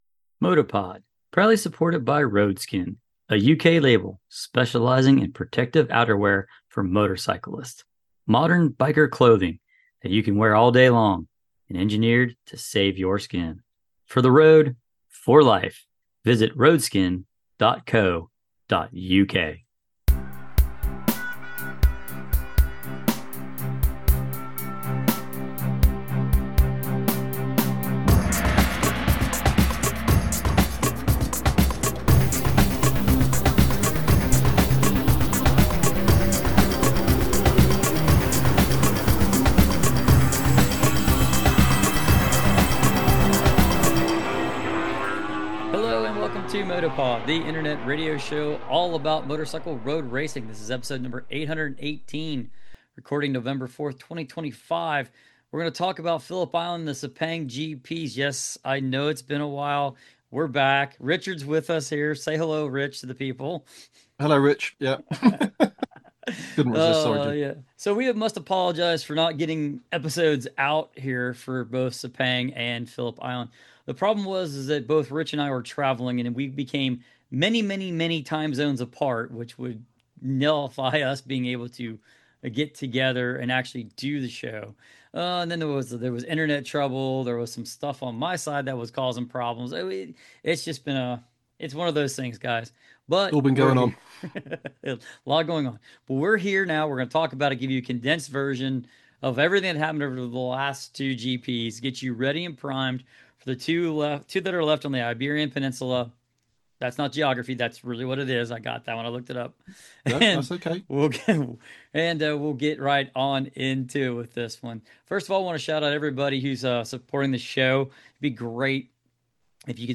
The internet radio show all about motorcycle road racing.